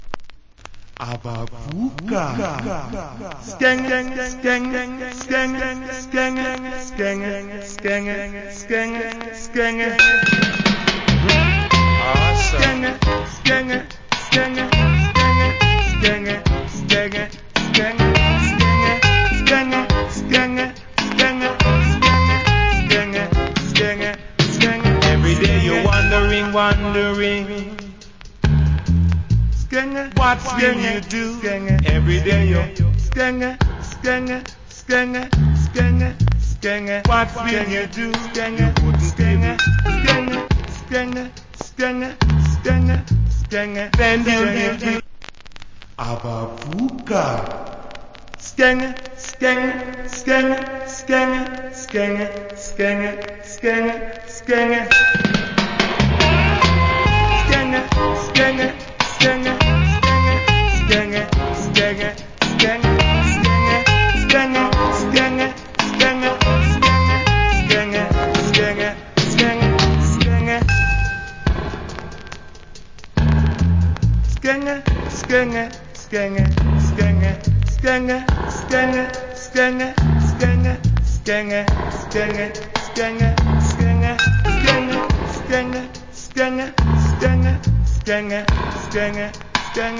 Roots Rock Vocacl.